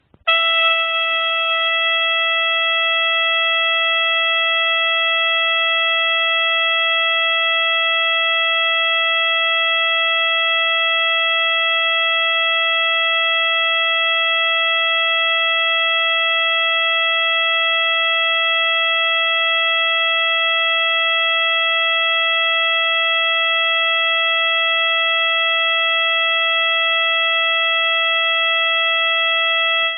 Звуки камертона
Звук камертона ноты ля 440 герц